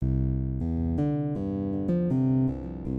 基于Chill 80 Bpm C
描述：慢节奏的柔和低音线，用果味循环制作。如果你使用它，请告诉我。
Tag: 80 bpm Chill Out Loops Bass Loops 516.97 KB wav Key : C